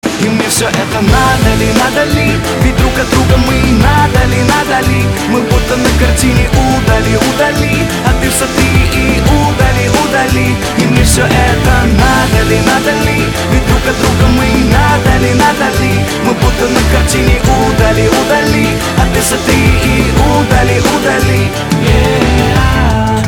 • Качество: 320, Stereo
мужской вокал
dance
club